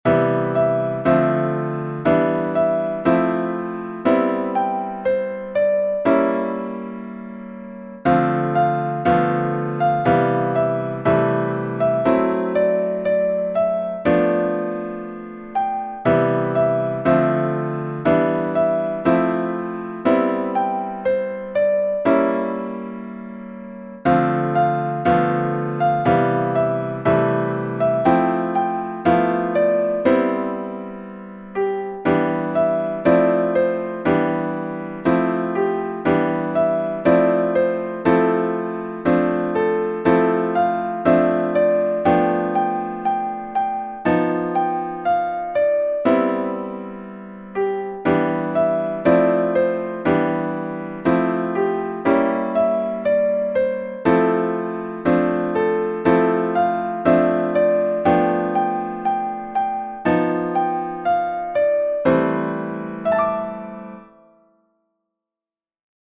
Rendu audio numérique